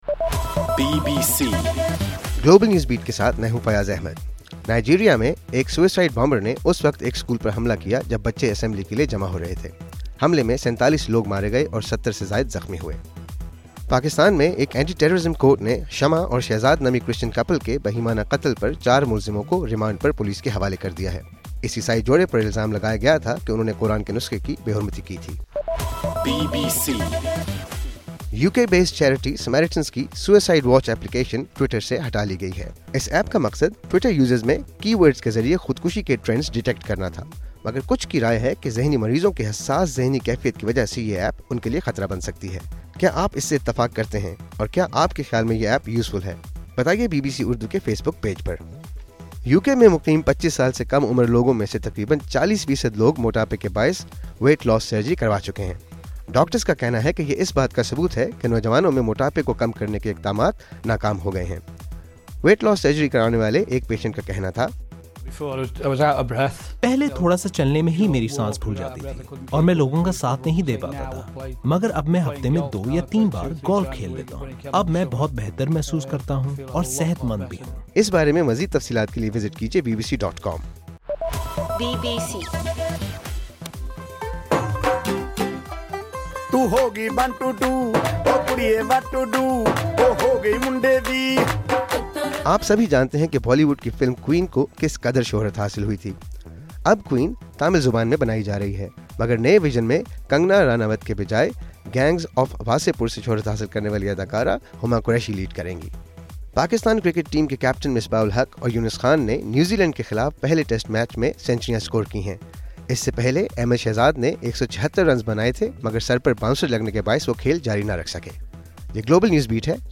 نومبر10 : رات 9 بجے کا گلوبل نیوز بیٹ بُلیٹن